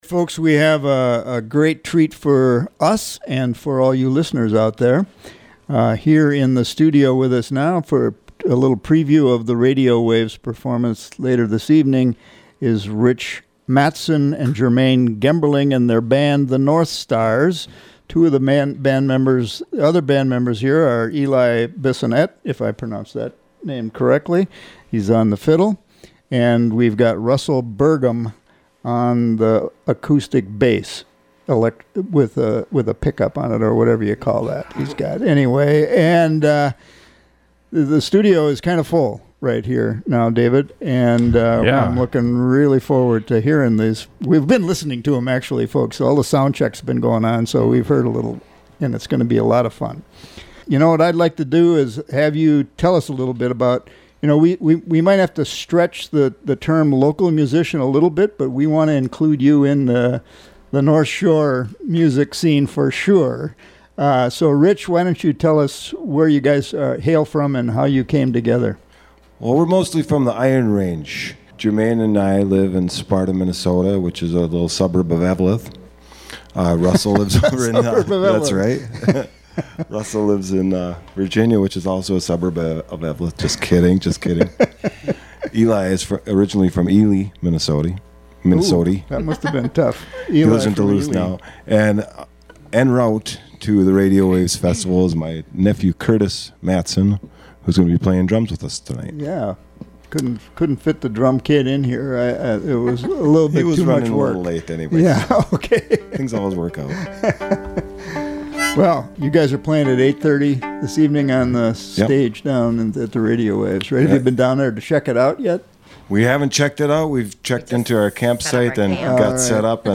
Listen for wonderful original music.